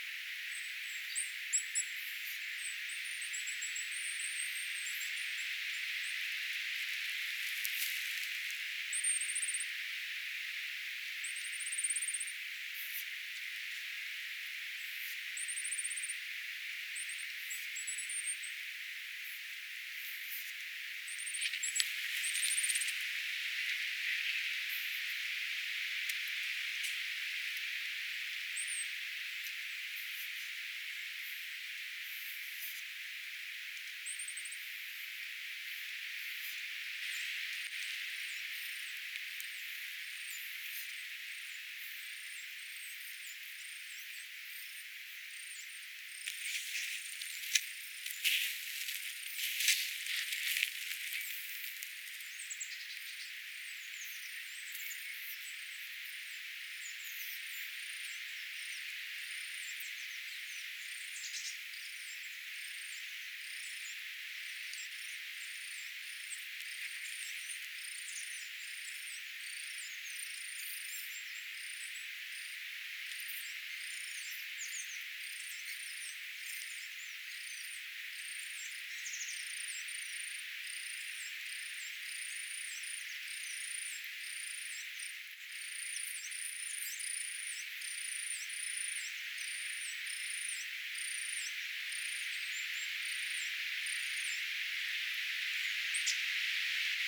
kuusitiainen visertää
kuusitiaisen_viserrysta.mp3